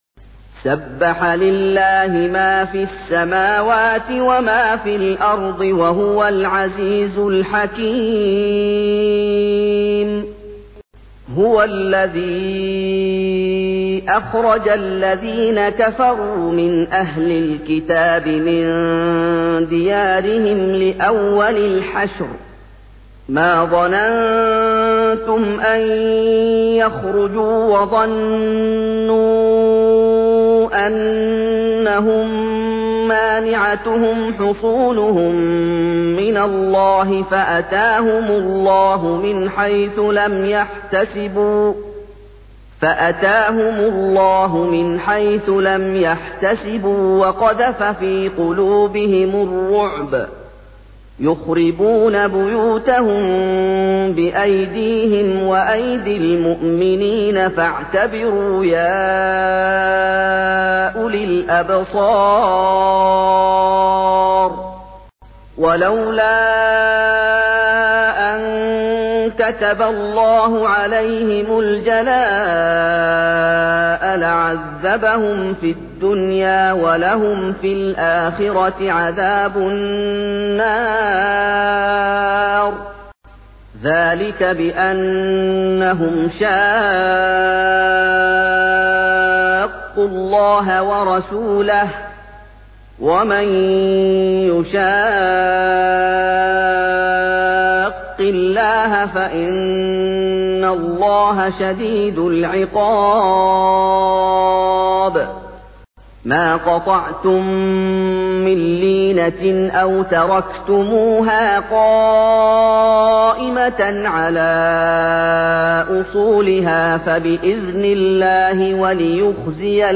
سُورَةُ الحَشۡرِ بصوت الشيخ محمد ايوب